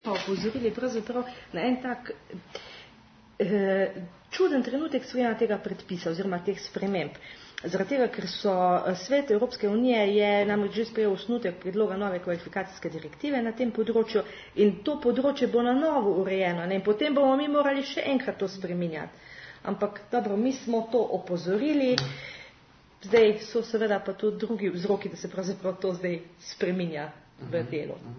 Zvočni posnetki izjave po srečanju (MP3)
Zakaj spreminjanje predpisov v tem trenutku? - govori mag. Kornelija Marzel, namestnica varuhinje